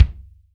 LA KICK 3.wav